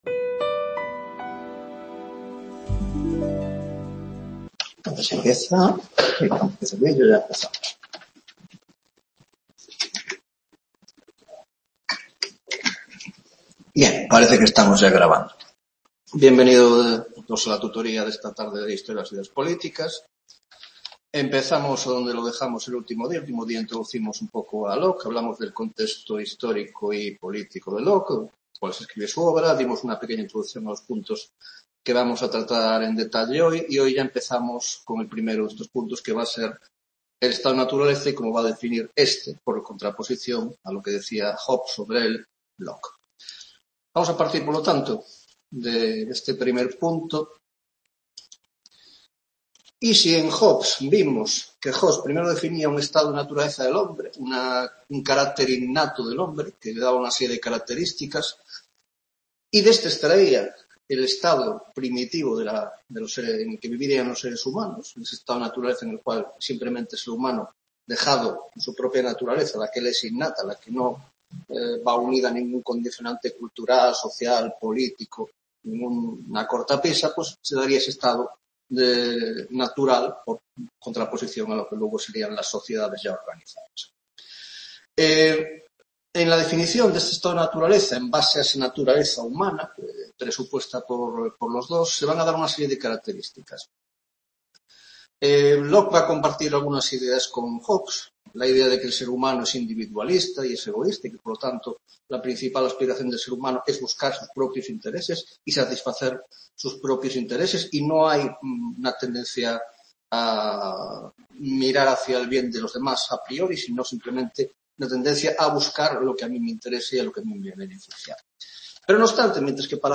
2ª Tutoría de Historia de las Ideas Políticas 2 (Grado de Ciencias Políticas)